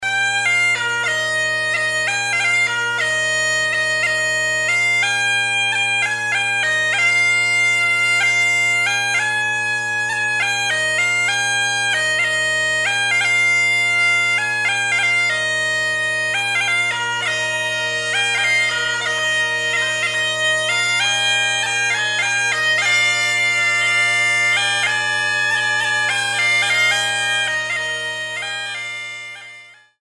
Il "baghèt"